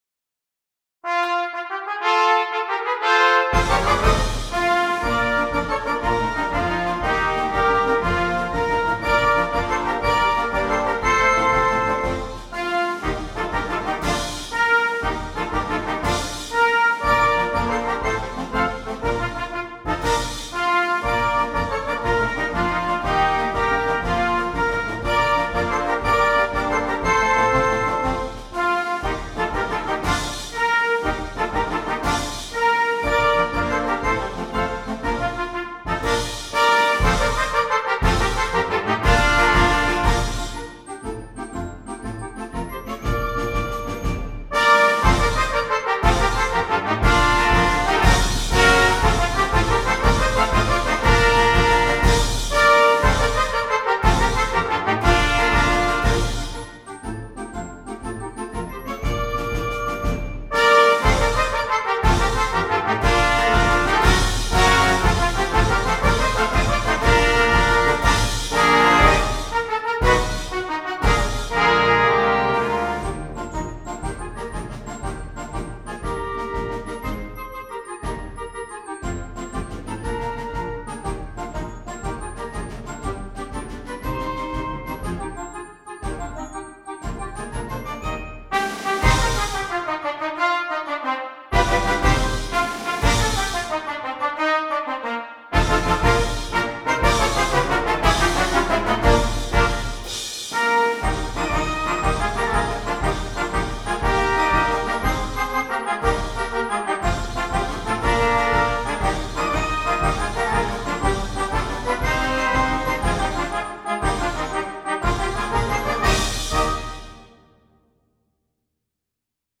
Concert Band
straightforward march style composition in 6/8 meter
Melody can be found in all parts.